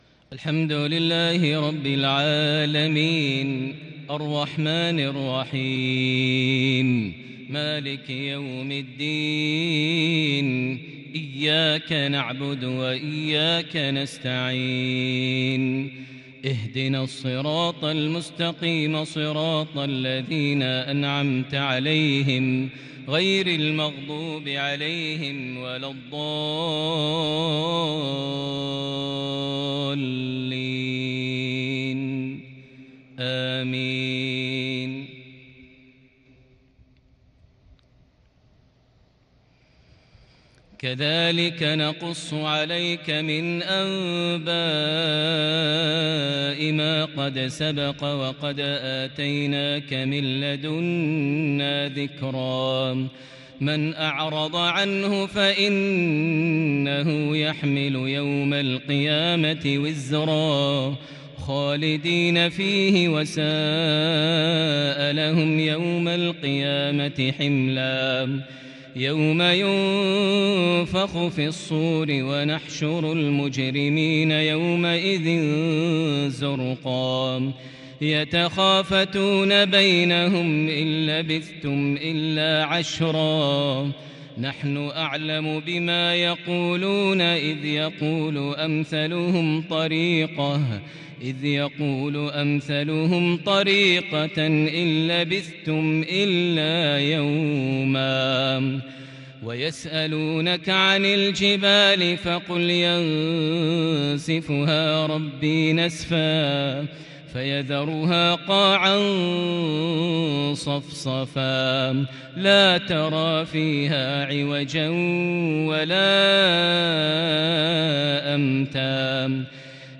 تنقلات كردية مؤثرة من سورتي طه - المنافقون | الأثنين 2 ذي الحجة 1442هـ > 1442 هـ > الفروض - تلاوات ماهر المعيقلي